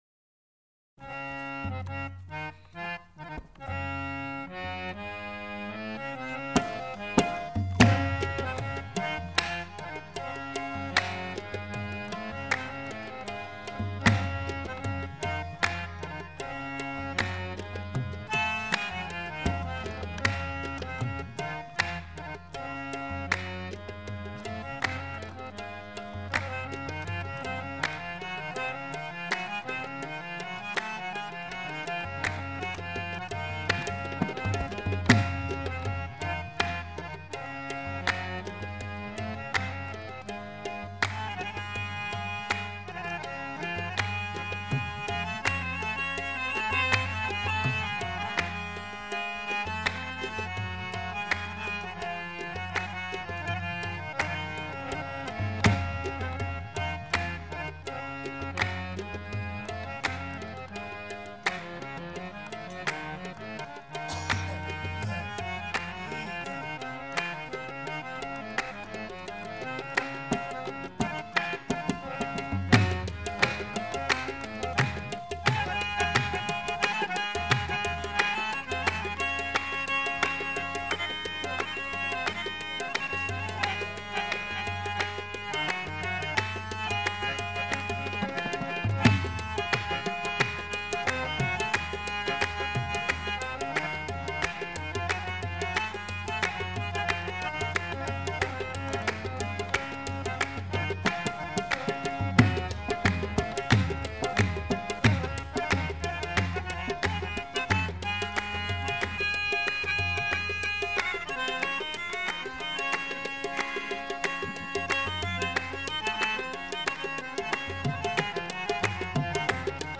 performed in form of a Qawwali.